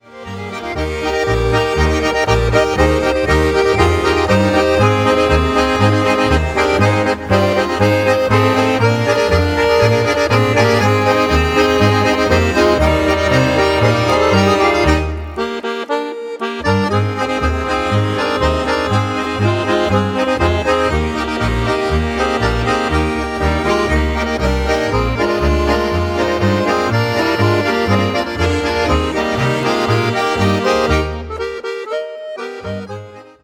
Marsch